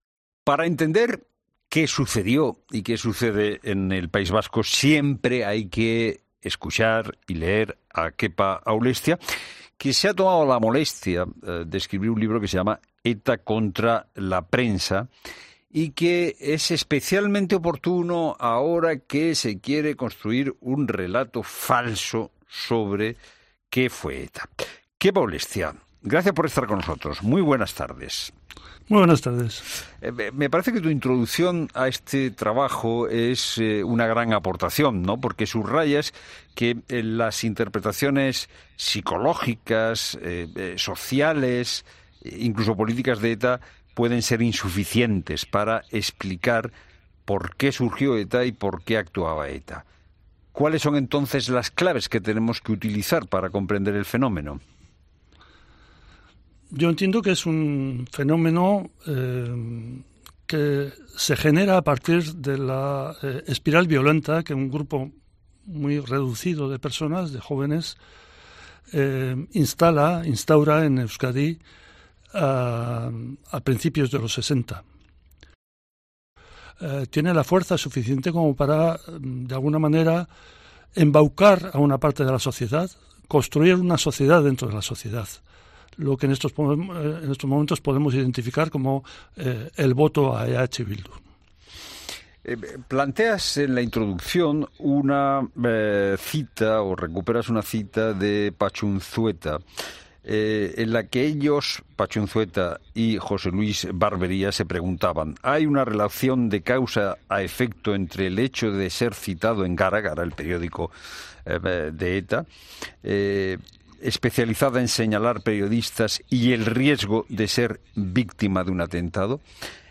Kepa Aulestia, político vasco y exsecretario general de Euskadiko Ezquerra, en 'La Tarde'